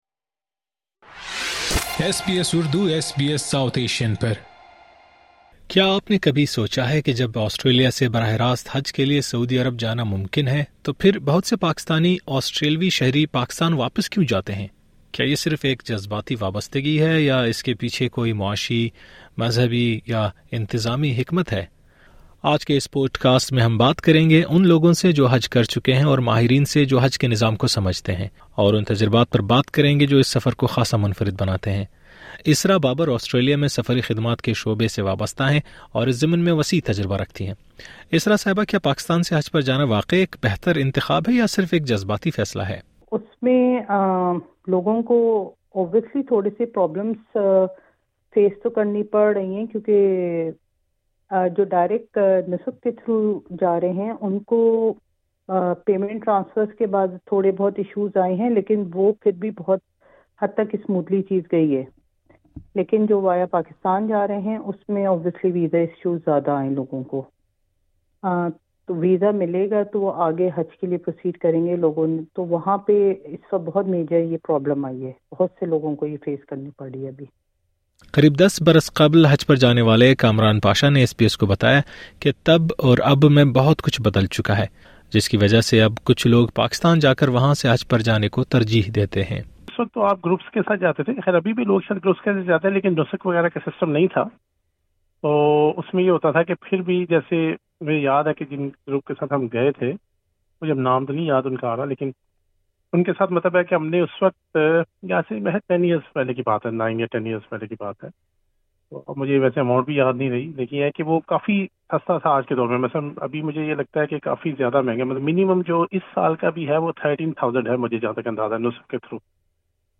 ہم بات کریں گے ان افراد سے جو حج کا تجربہ رکھتے ہیں اور اُن ماہرین سے بھی جو حج کے نظام اور اس سے جُڑی پالیسیوں کو بخوبی سمجھتے ہیں۔